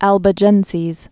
(ălbə-jĕnsēz)